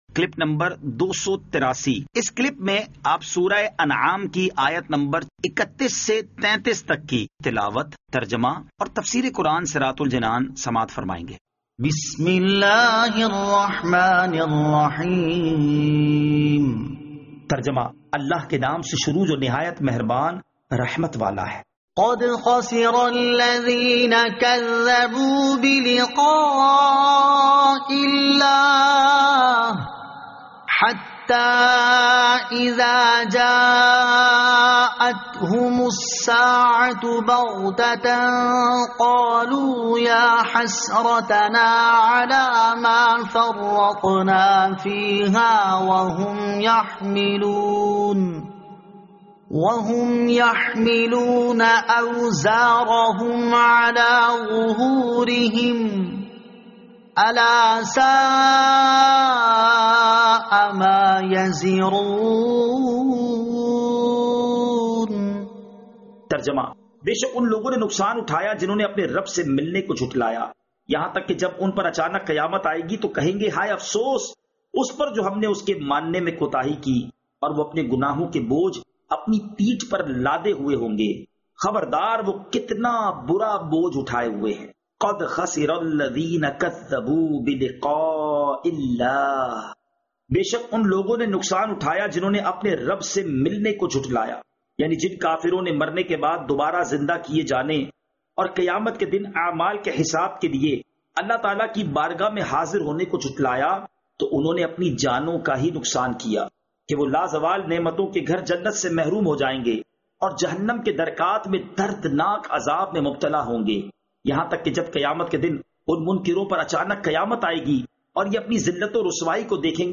Surah Al-Anaam Ayat 31 To 33 Tilawat , Tarjama , Tafseer
2021 MP3 MP4 MP4 Share سُوَّرۃُ الأنعام آیت 31 تا 33 تلاوت ، ترجمہ ، تفسیر ۔